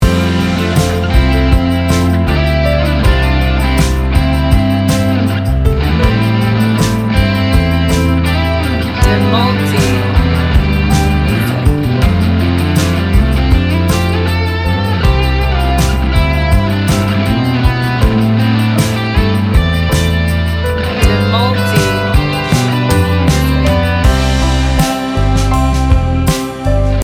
Ini adalah lagu yang seperti pelukan lembut.